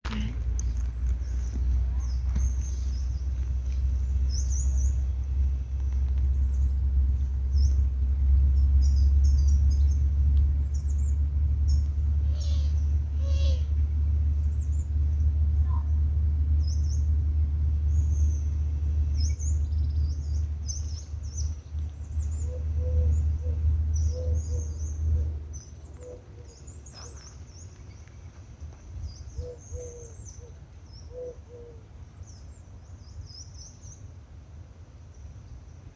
Carbonero Garrapinos (Periparus ater)
Nombre en inglés: Coal Tit
Localización detallada: Jardin Botanico
Condición: Silvestre
Certeza: Vocalización Grabada
periparus-ater-J-BOT-BAR5-4-10.mp3